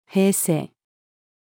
平静-female.mp3